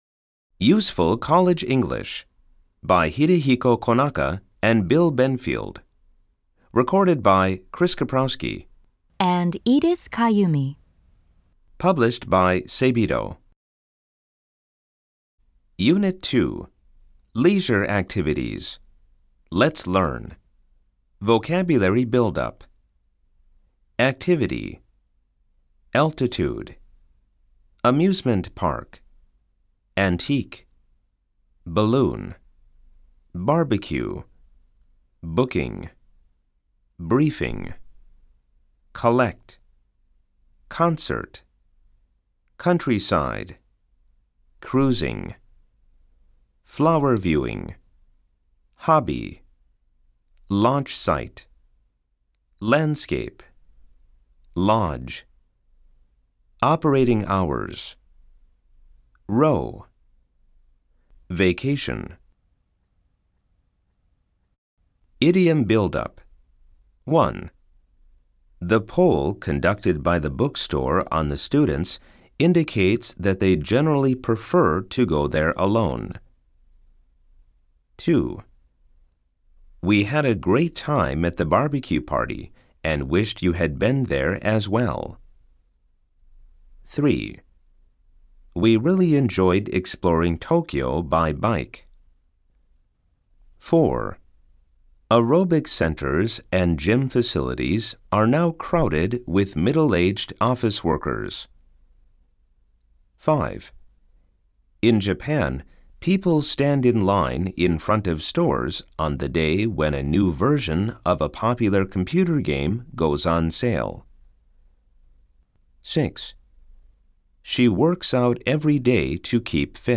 吹き込み Amer E